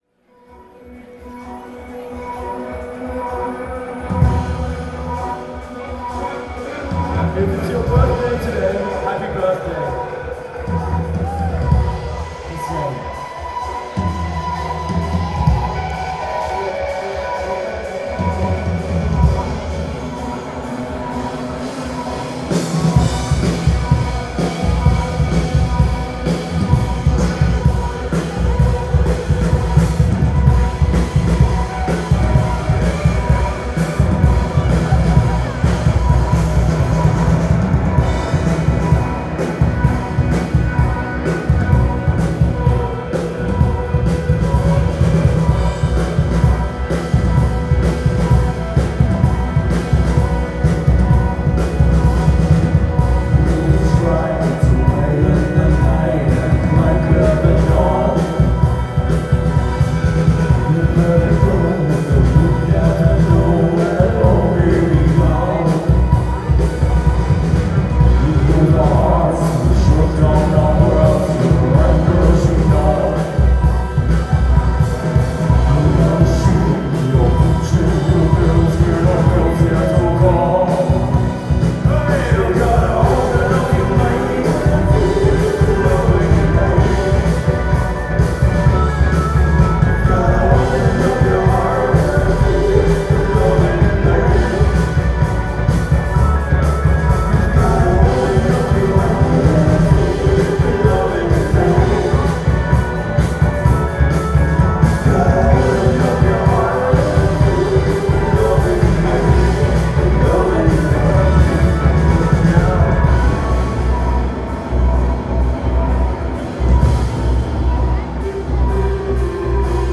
Brooklyn Bowl 07-24-10